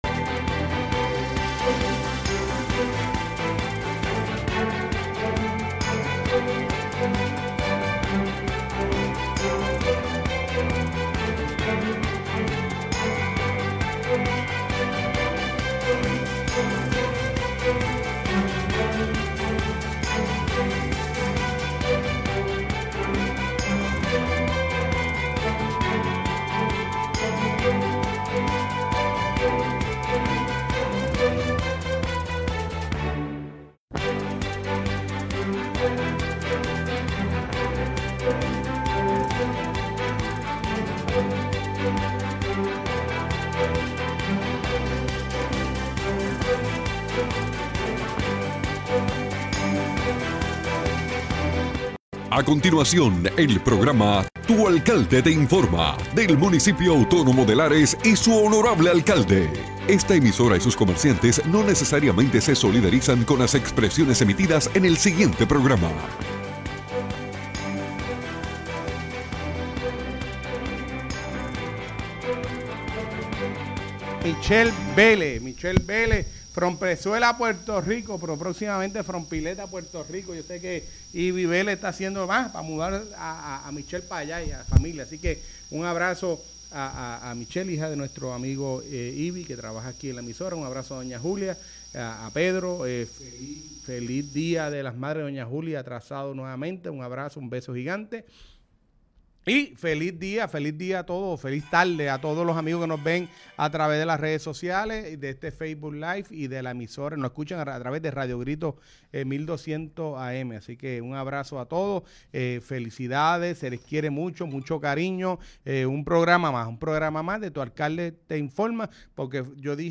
El honorable alcalde de Lares, Fabián Arroyo, junto a su equipo de trabajo nos informan sobre todo las novedades del municipio.